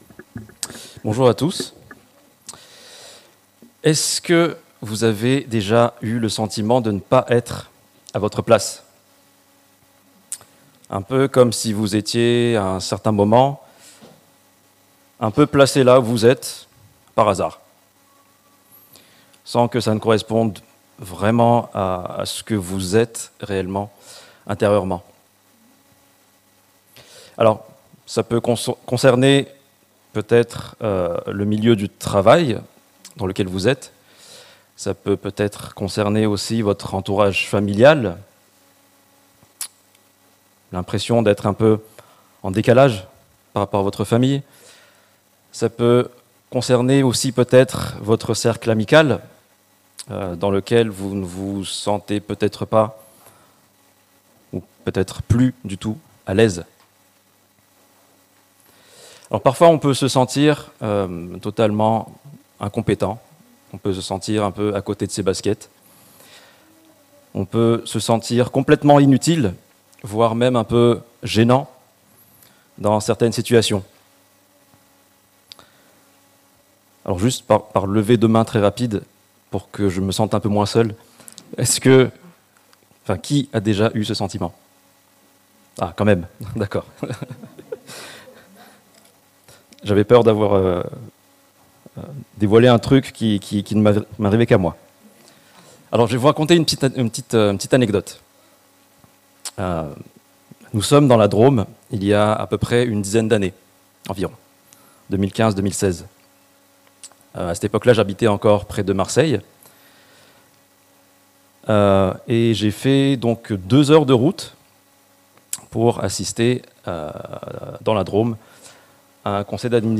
L'assurance du salut - Prédication de l'Eglise Protestante Evangélique de Crest sur l'épître de Jean